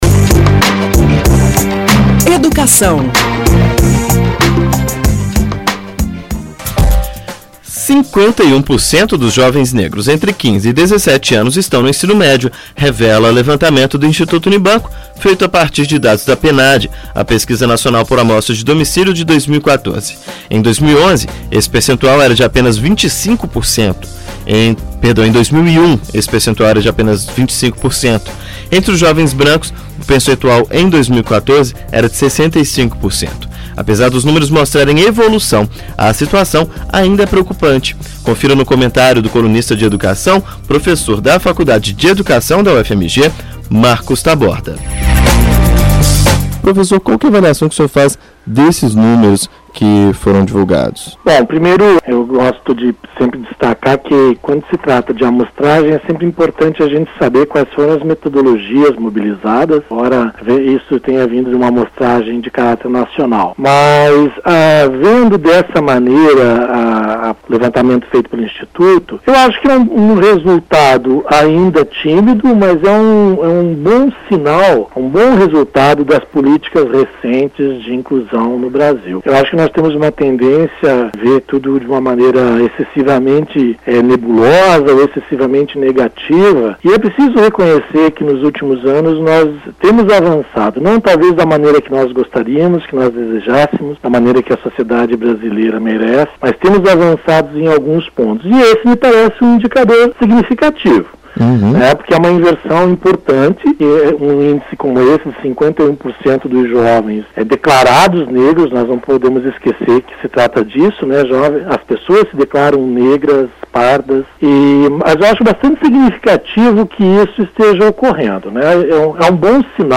Na coluna Educação em pauta o professor